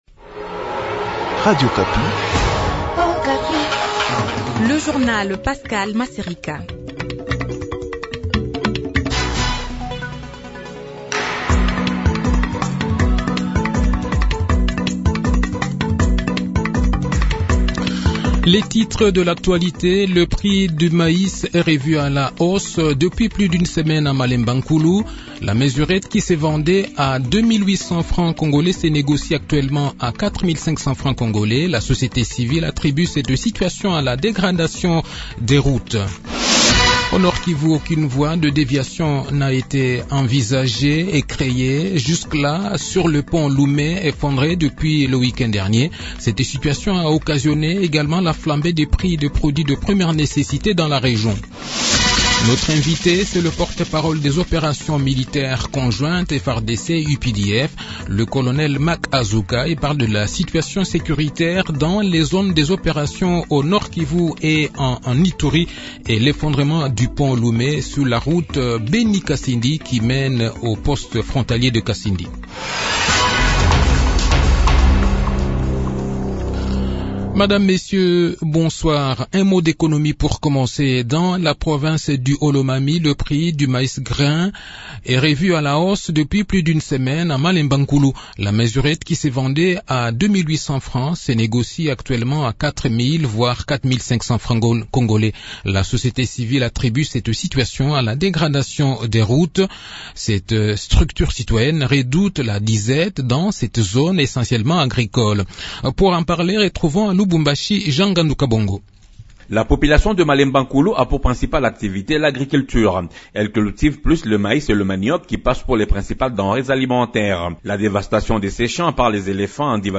Le journal de 18 h, 14 decembre 2022